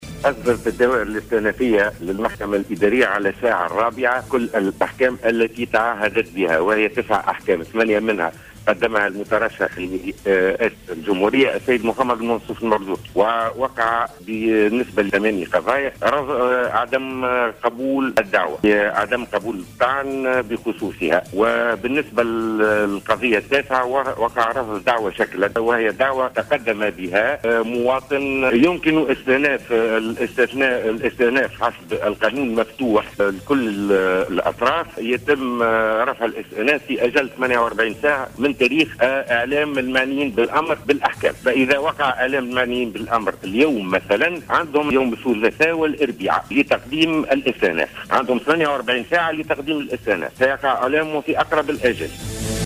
أكد الرئيس الأول للمحكمة الإدارية محمد فوزي بن حمّاد في تصريح ل"جوهرة أف أم" أن المحكمة الإدارية قرّرت رفض قضايا الطعون التي تقدم بها المرشح للدور الثاني للرئاسية منصف المرزوقي و مواطن غير مترشح.